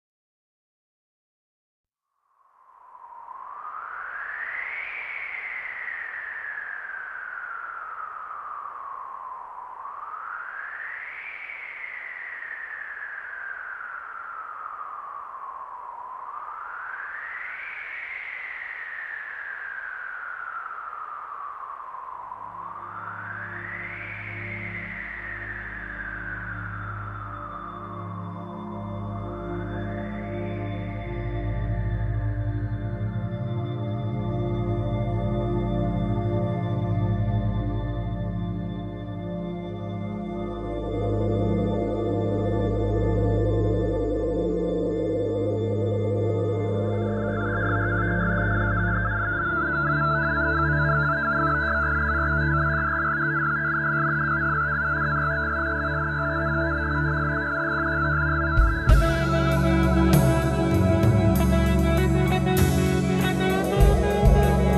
Italian prog band
melodic progressive concept albums